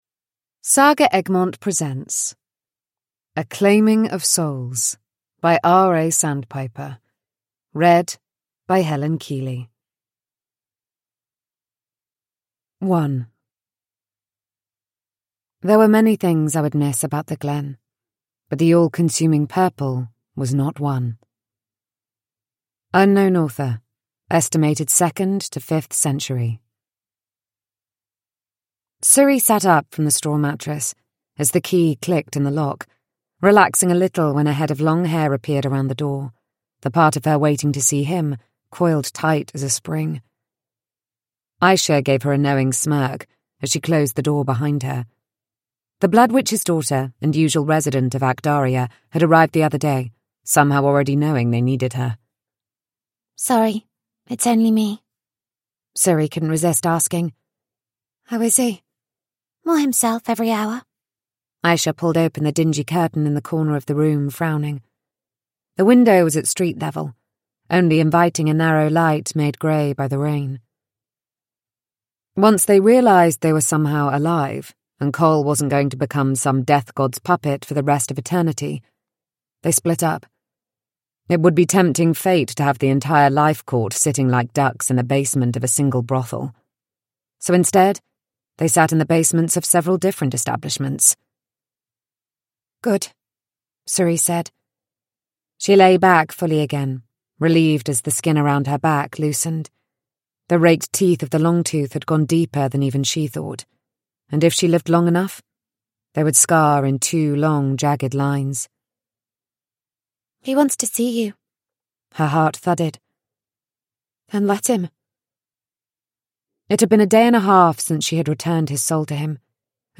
A Claiming of Souls: A gripping, high-stakes desert fantasy romance – Ljudbok